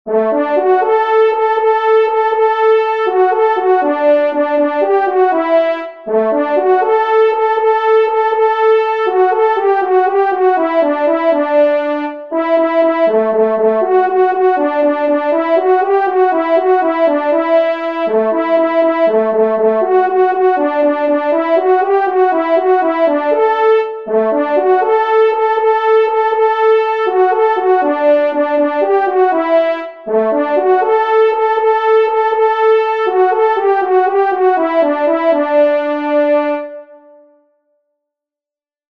Musique Synthé “French Horns” (Tonalité de Ré